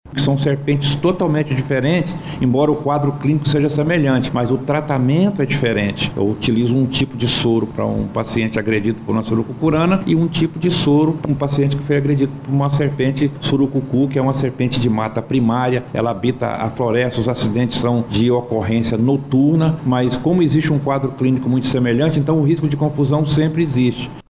como explica o médico infectologista